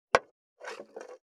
474,台所,野菜切る,咀嚼音,
効果音厨房/台所/レストラン/kitchen食器食材